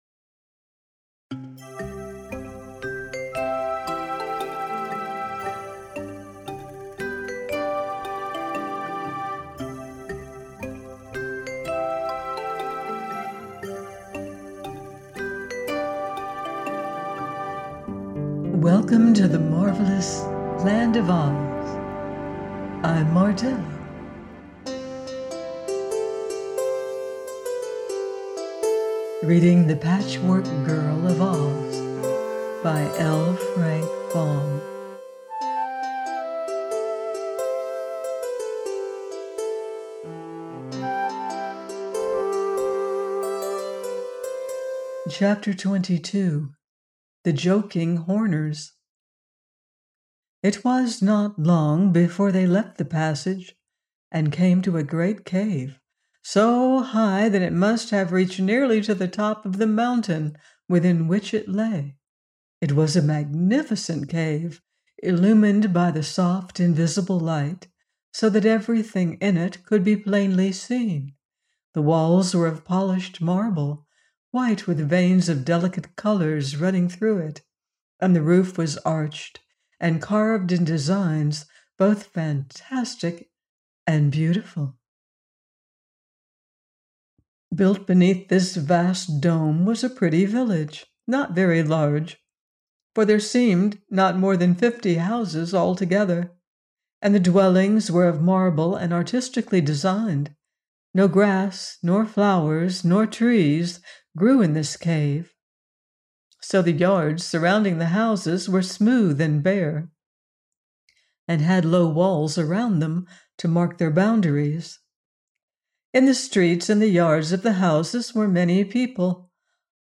The Patchwork Girl of Oz – by L. Frank Baum - audiobook